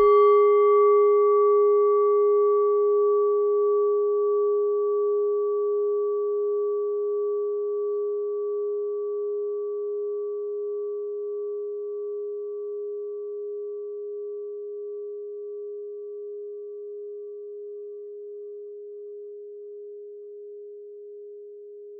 Klangschale Nepal Nr.31
Klangschale-Gewicht: 950g
Klangschale-Durchmesser: 14,5cm
(Ermittelt mit dem Filzklöppel)
klangschale-nepal-31.wav